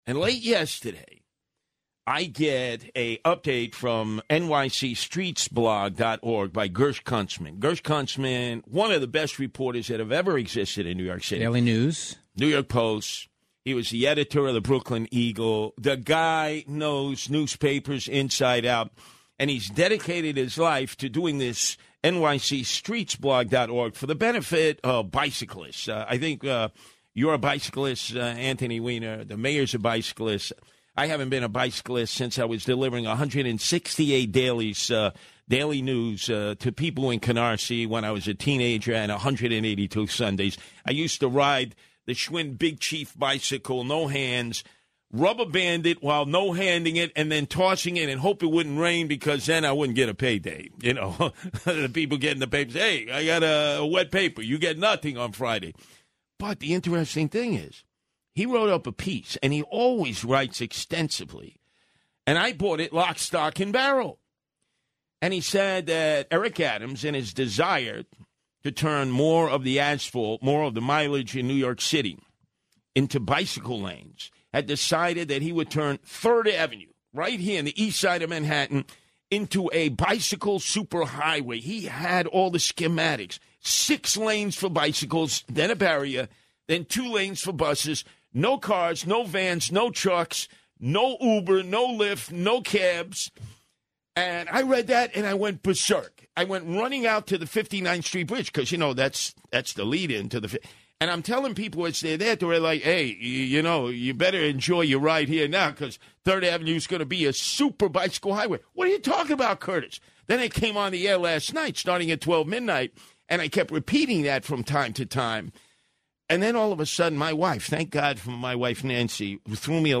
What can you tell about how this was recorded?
In fact, taking the airwaves on Saturday morning, he repeated the story over and over, presenting it as truth.